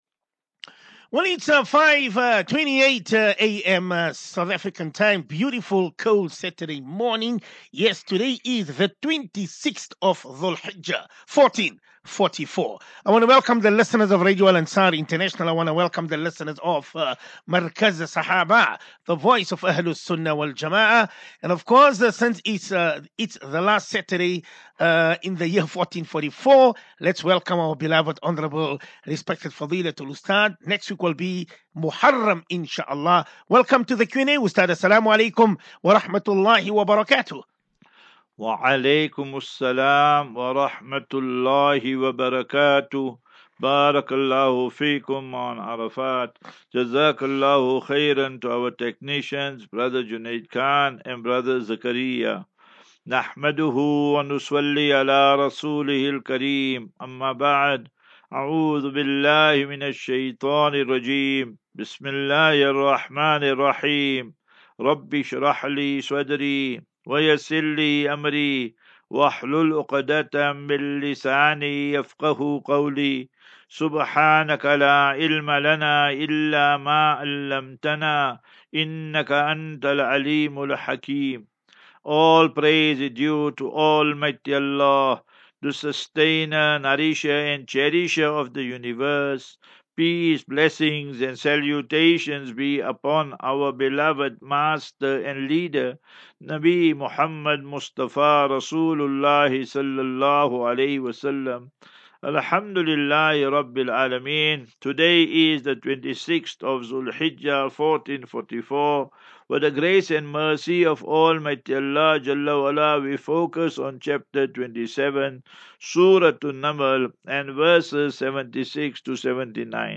As Safinatu Ilal Jannah Naseeha and Q and A 15 Jul 15 July 2023.